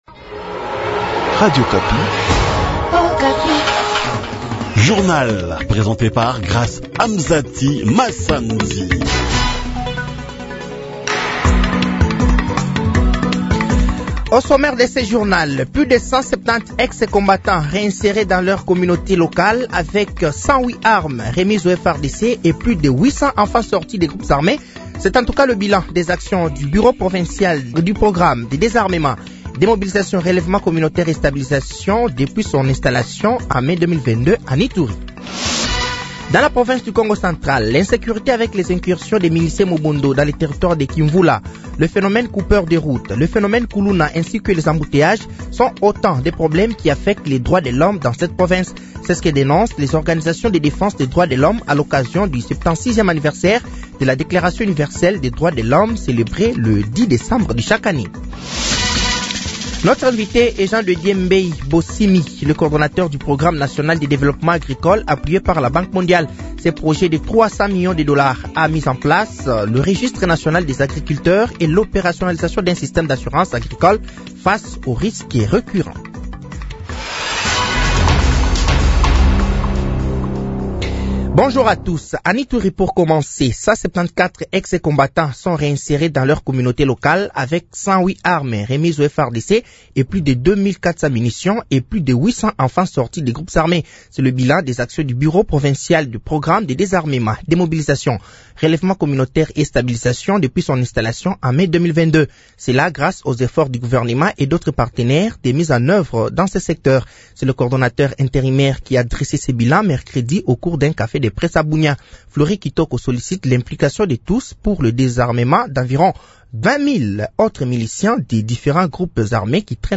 Journal français de 8h de ce vendredi 13 décembre 2024